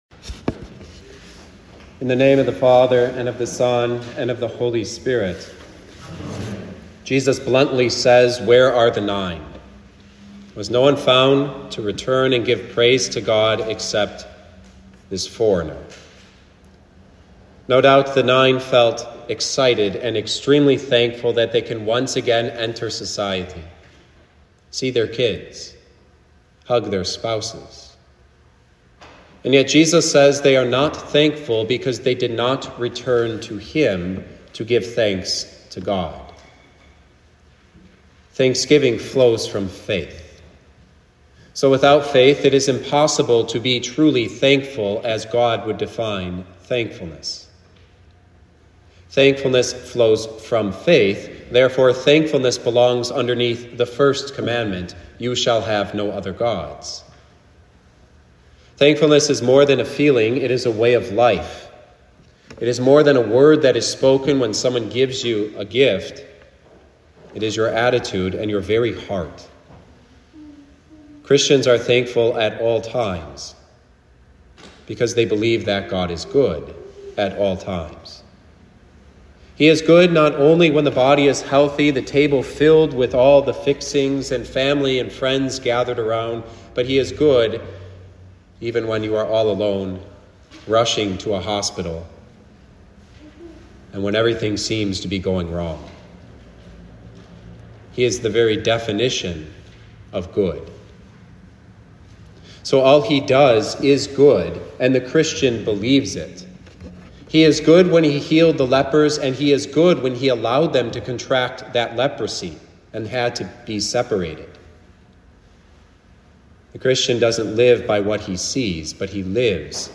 Thanksgiving – Living Word Lutheran Church